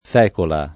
fecola [ f $ kola ] s. f.